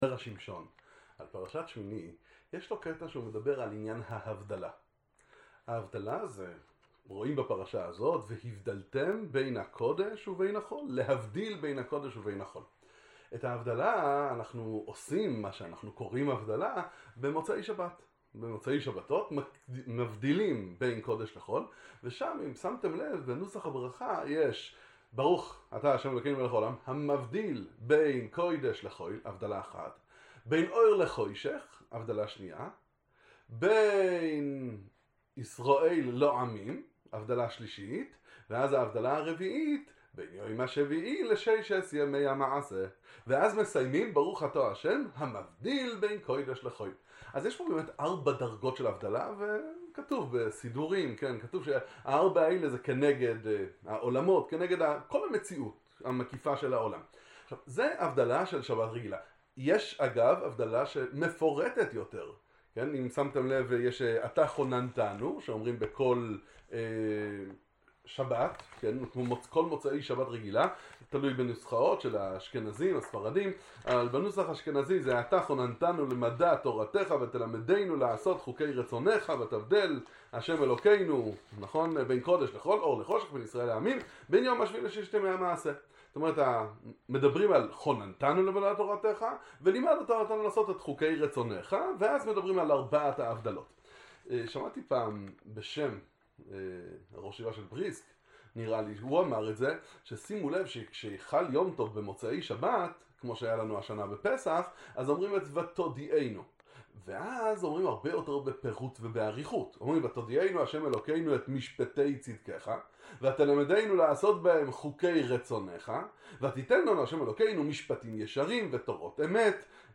דבר תורה מהספר המסוגל זרע שמשון